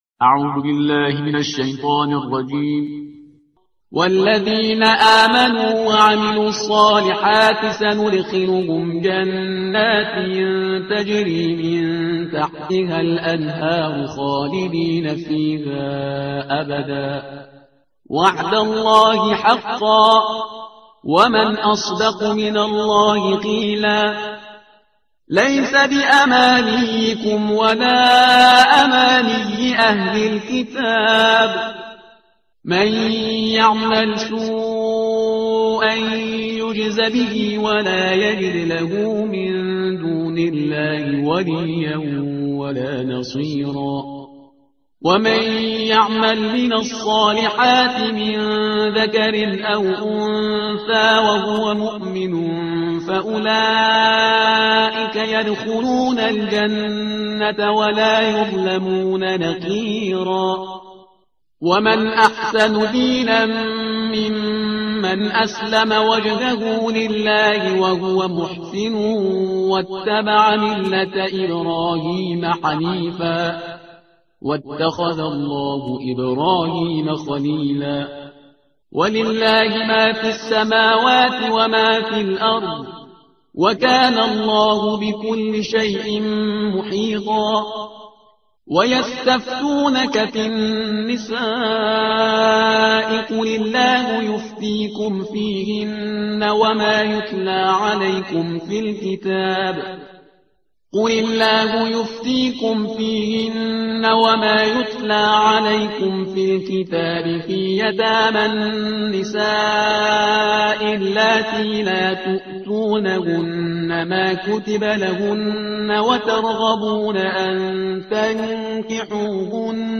ترتیل صفحه 98 قرآن با صدای شهریار پرهیزگار